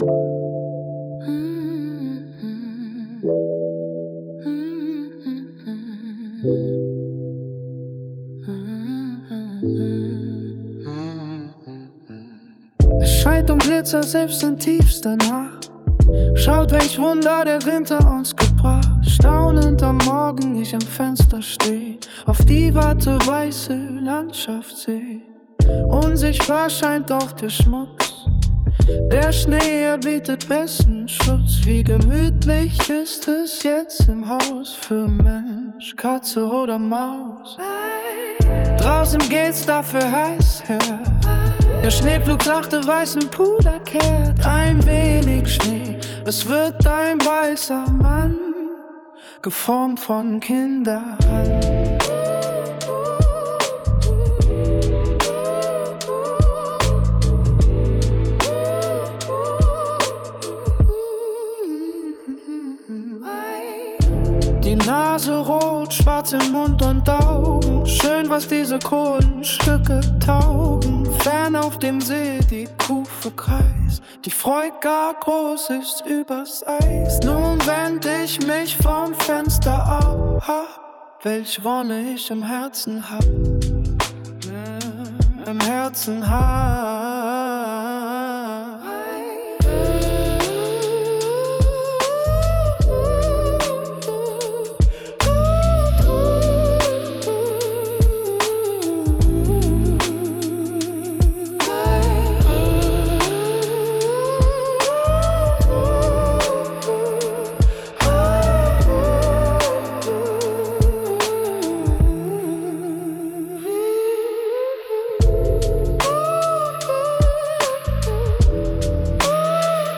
Sänger, Melodic Trap, Downtempo